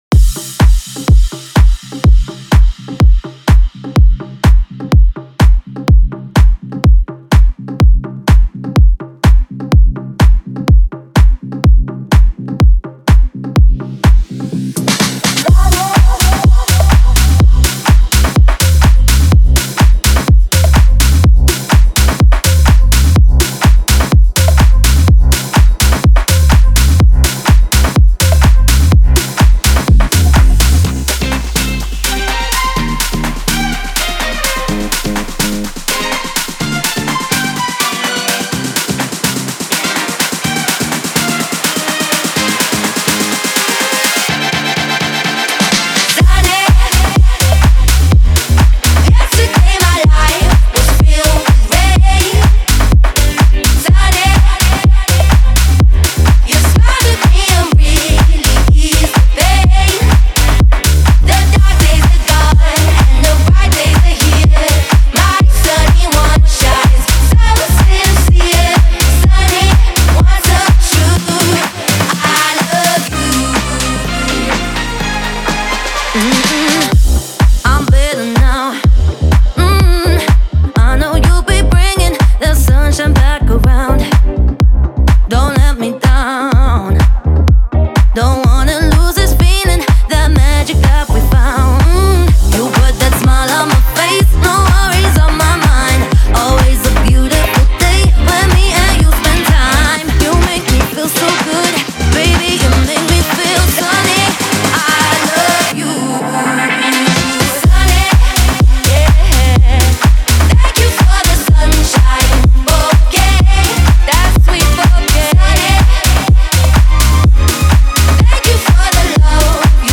• Жанр: House, Dance